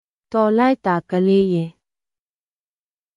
トーライター　カリーイェ
当記事で使用された音声（日本語およびミャンマー語）はGoogle翻訳　および　Microsoft Translatorから引用しております。